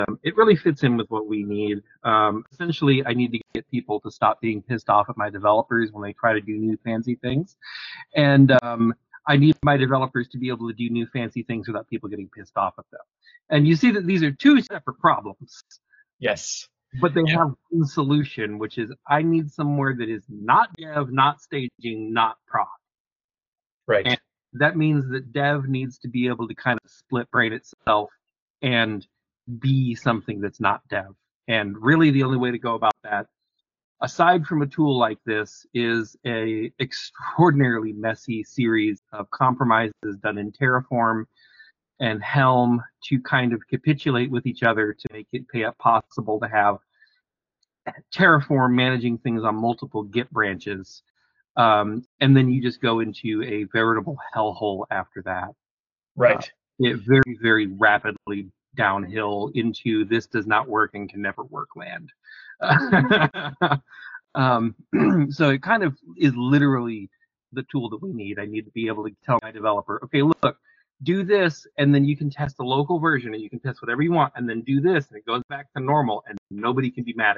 Listen to a CIVO DevOps leader describe Codezero in his own words.